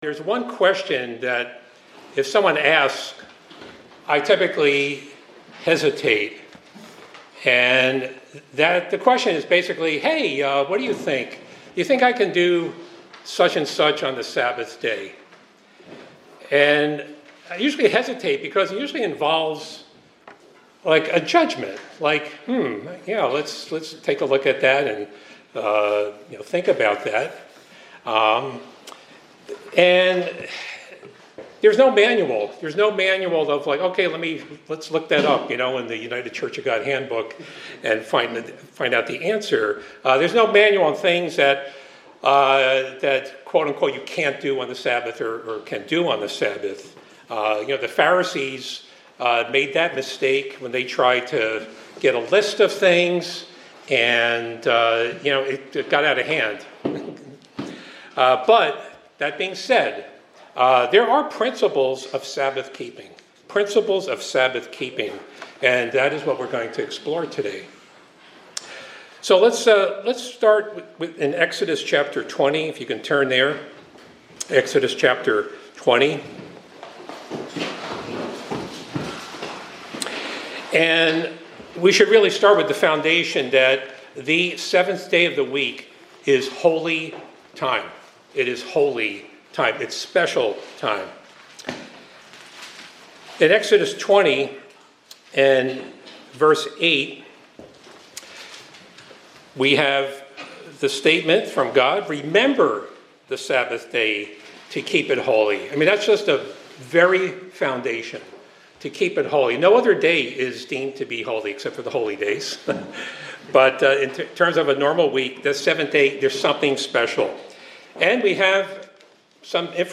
This sermon discusses the principles of Sabbath keeping, emphasizing its importance as a holy time for worship, rest, and reflection. It highlights that the Sabbath, rooted in the commandment from Exodus 20, is a day to refrain from work and engage in activities that honor God, such as worship, fellowship, and spending time in nature.
Given in Hartford, CT